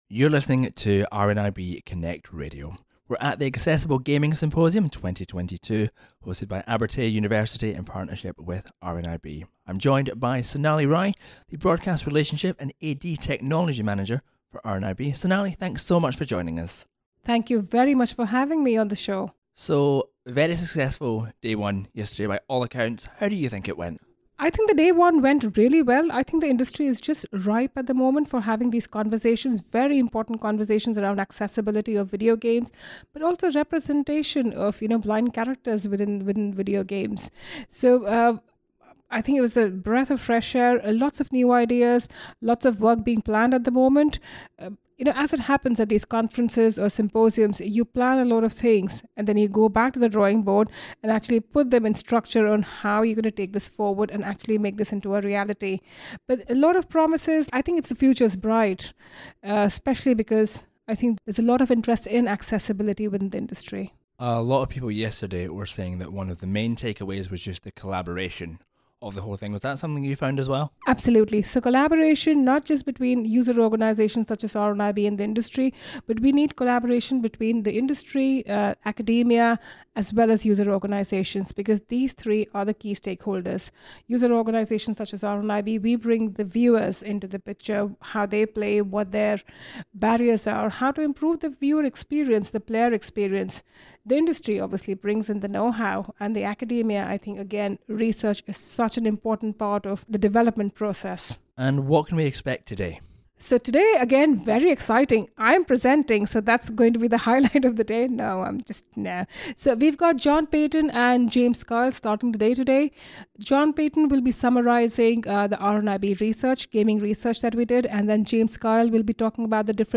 Day 2 of the Accessible Gaming Symposium 2022 took place in Dundee on Thursday 6th of October.